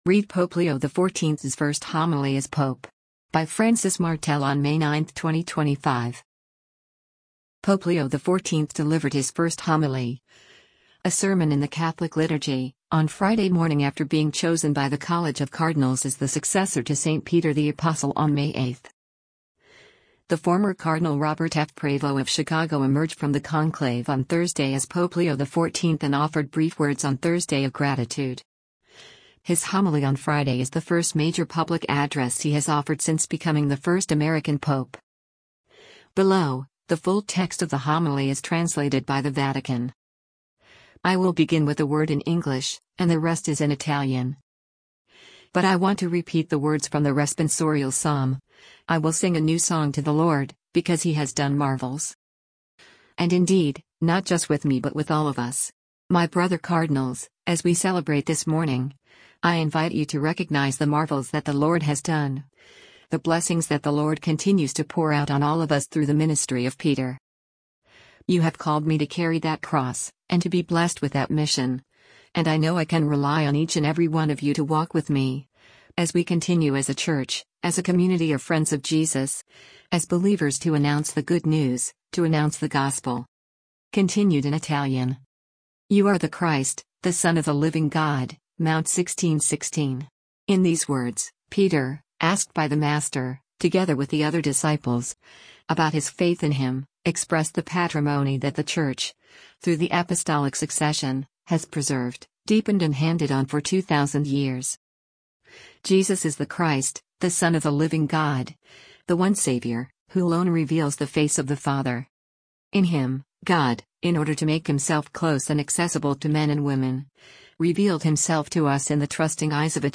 Pope Leo XIV delivered his first homily, a sermon in the Catholic liturgy, on Friday morning after being chosen by the College of Cardinals as the successor to St. Peter the Apostle on May 8.